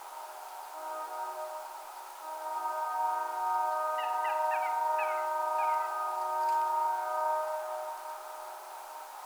Common Gallinule (Gallinula galeata)
A secretive marsh bird, I am not very familiar with the Common Gallinule, and have had very little exposure to this species in the wild. I must have stepped away from the mic when this bird called, which would have been a county first for me if I had been listening live. I am not aware of how often they call like this during nocturnal migration, but it was a pretty exciting find!